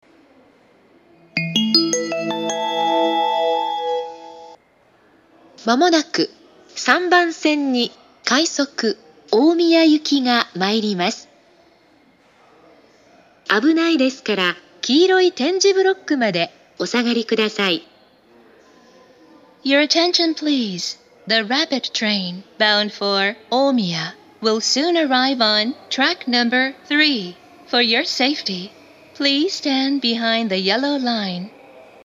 ３番線接近放送